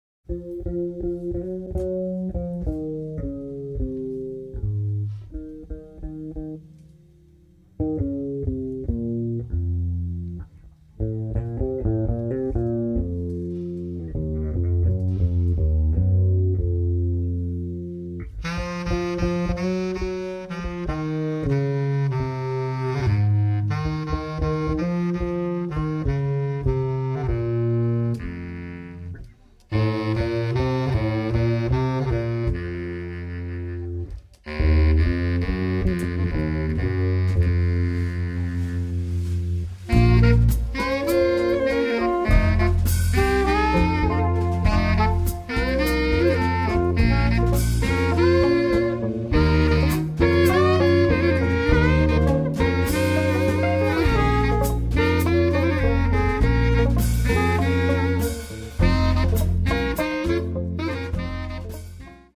sassofono tenore e baritono, clarinetto basso
sassofono alto e soprano
chitarra
basso
batteria
registrato in Svizzera